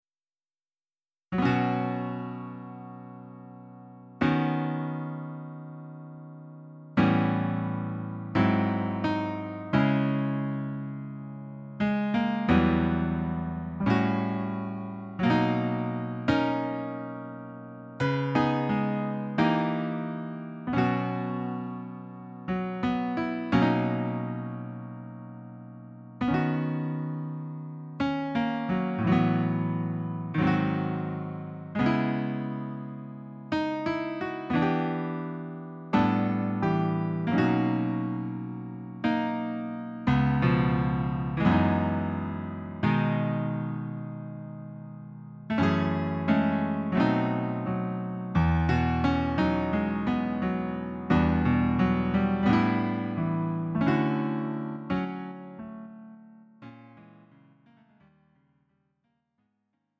음정 -1키 3:44
장르 가요 구분 Lite MR